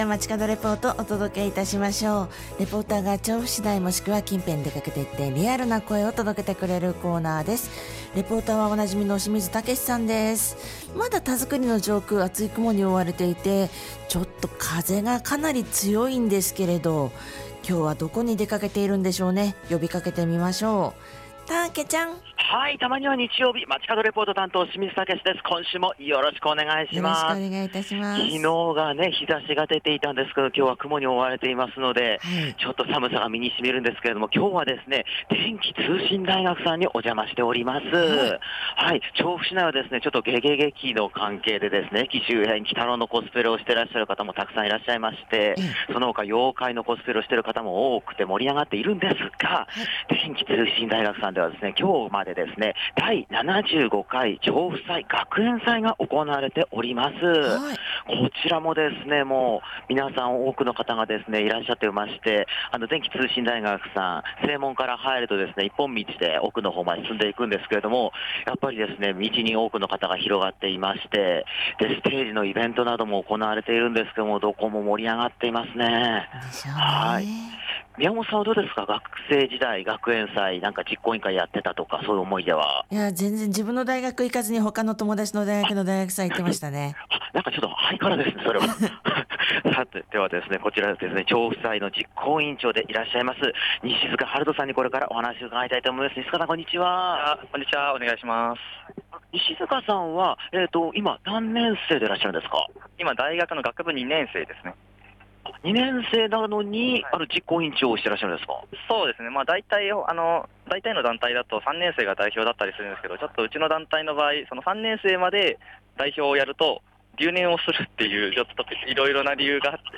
厚い雲が覆っている空の下からお届けした本日は、電気通信大学さんから「第75回調布祭」のレポートです！
会場は、大学生の元気いっぱいの熱気で盛り上がっていました